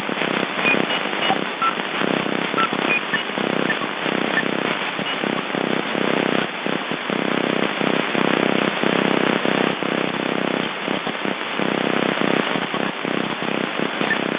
Sound_160m_AM